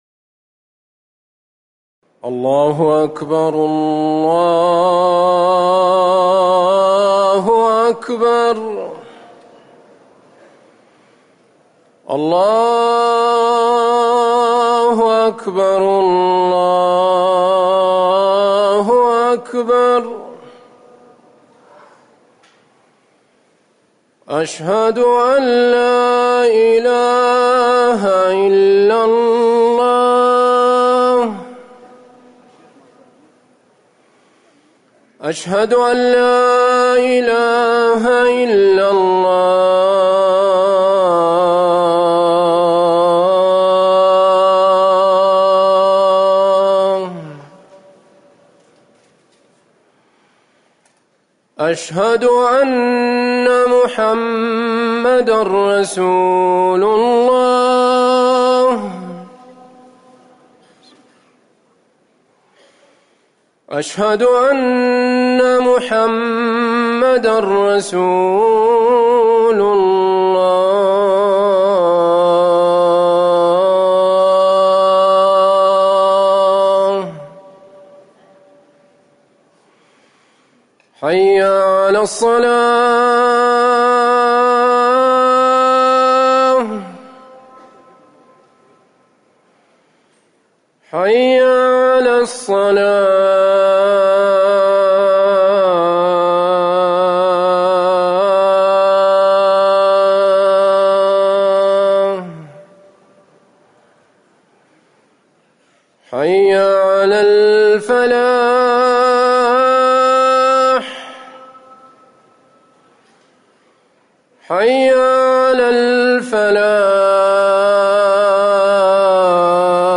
أذان المغرب
تاريخ النشر ١٥ صفر ١٤٤١ هـ المكان: المسجد النبوي الشيخ